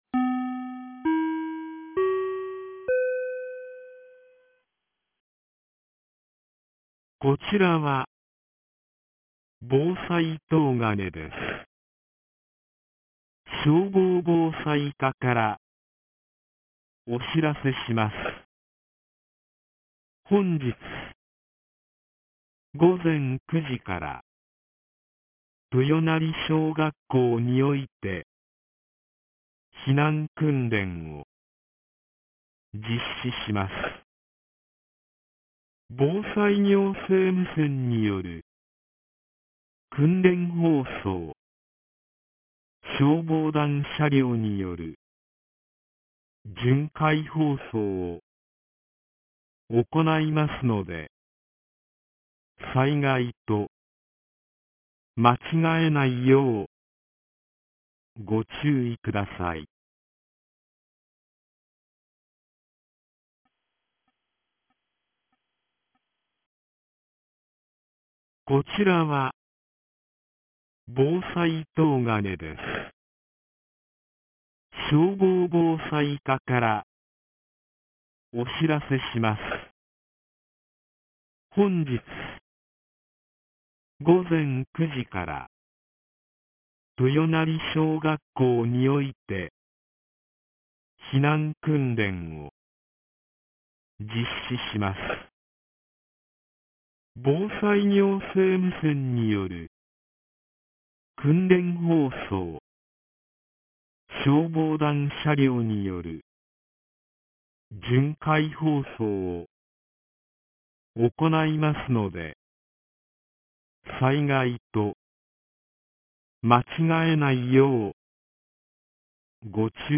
2025年11月01日 08時02分に、東金市より防災行政無線の放送を行いました。